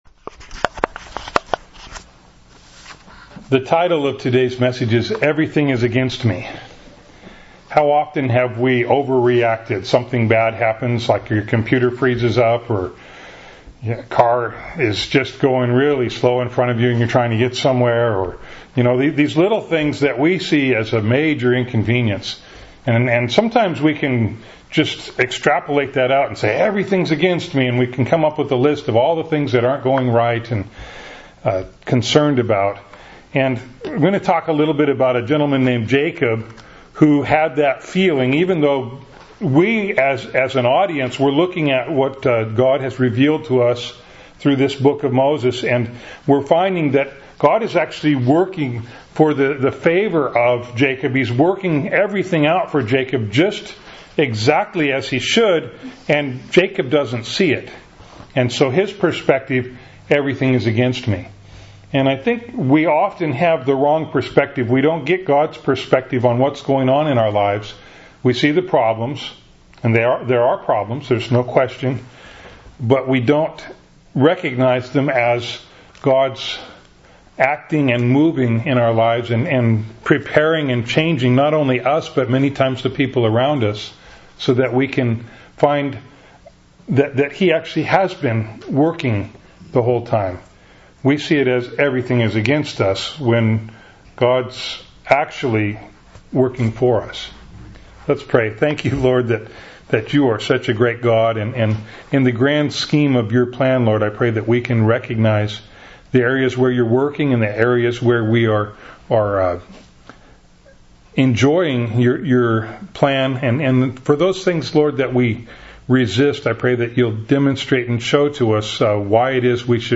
Bible Text: Genesis 42:1-38 | Preacher